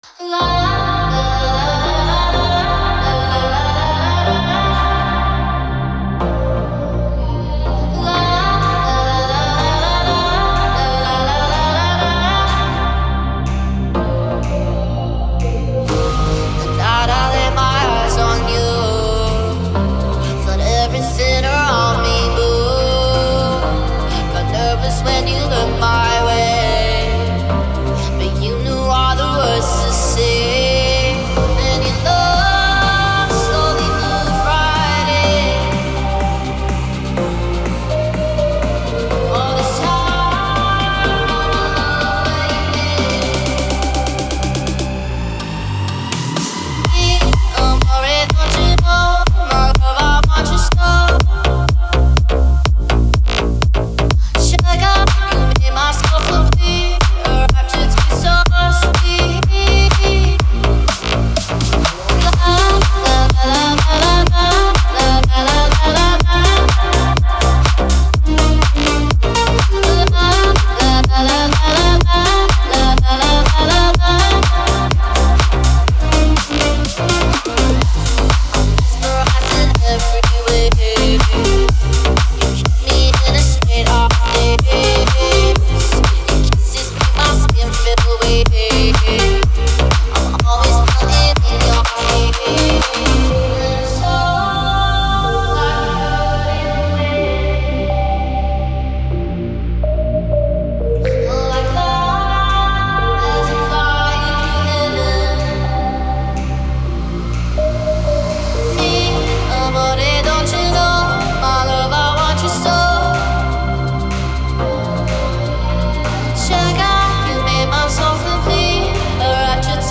это захватывающая электронная композиция
выполненная в жанре EDM с элементами даунтемпо.
сочетая мелодичные синтезаторы